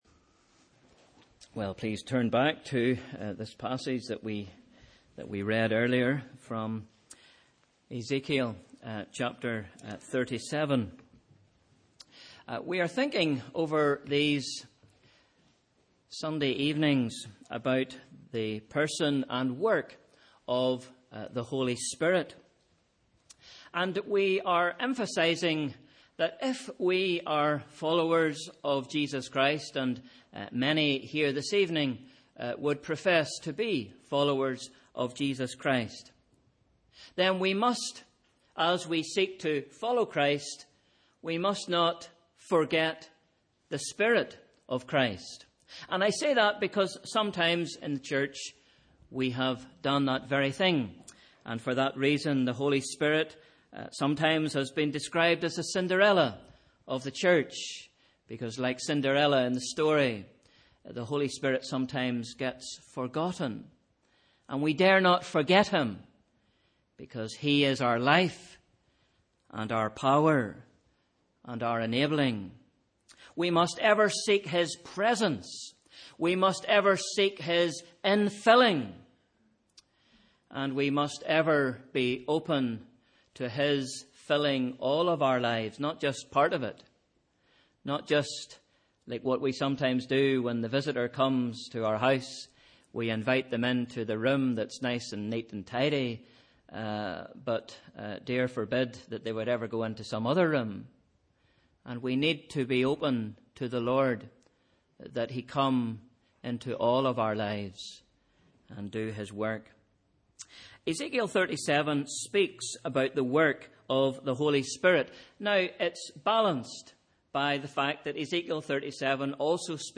Sunday 24th January 2016 – Evening Service